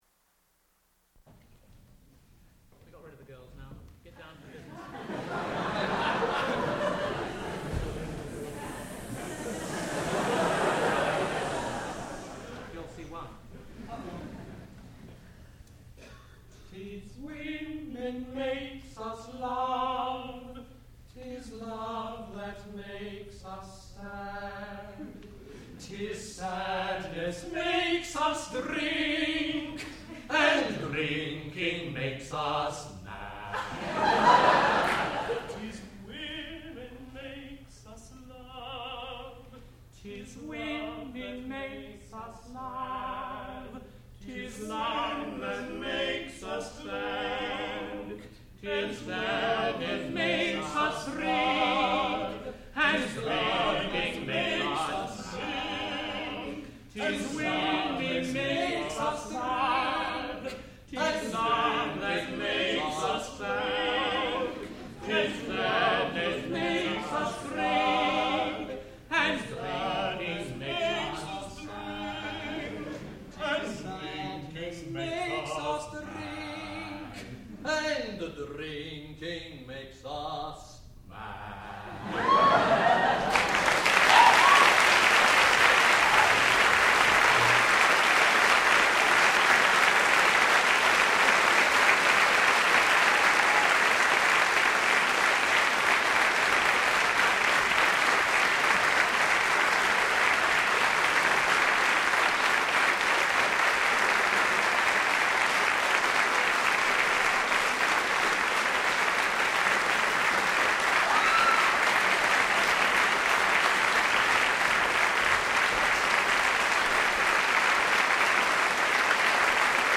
sound recording-musical
classical music
tenor
soprano
lute